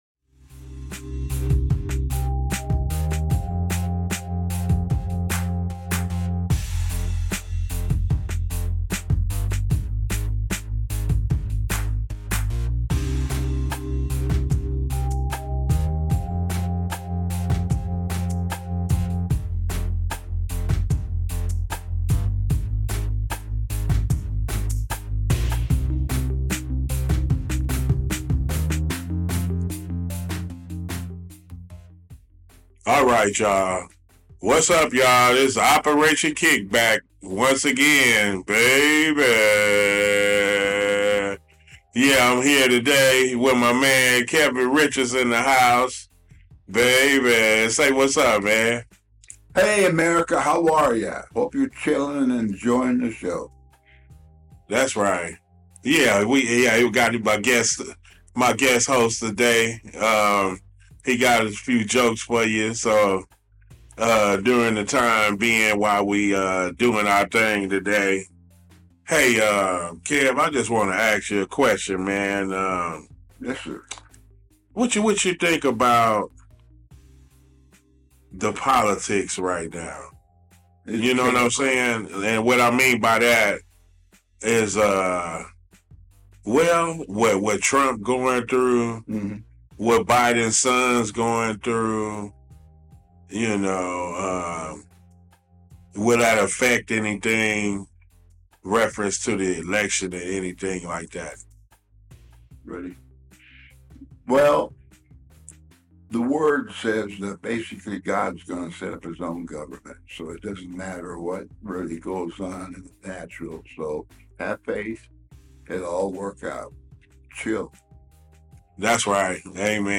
This episode of CityHeART Radio’s Operation KickBack aired live on CityHeART Radio Tuesday June 11 at 1pm.